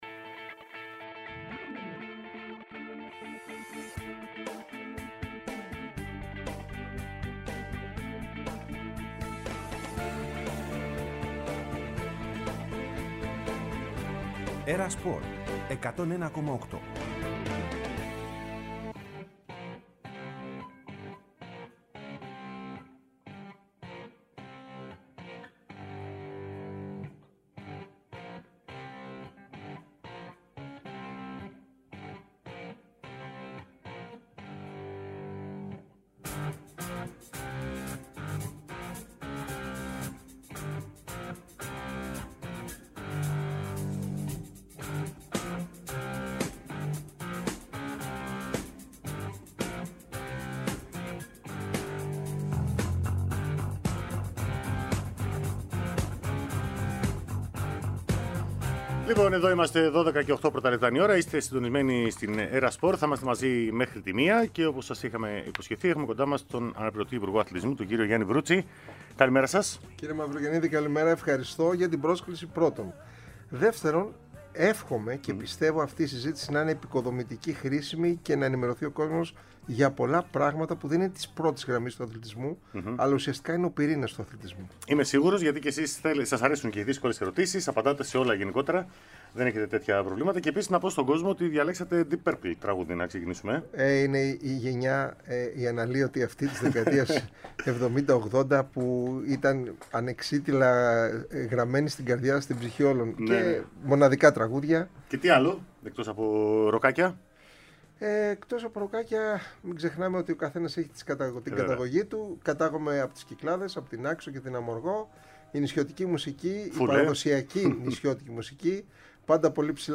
ΕΡΑ ΣΠΟΡ Δοκαρι και Γκολ Εκπομπές ΣΥΝΕΝΤΕΥΞΕΙΣ Γιαννης Βρουτσης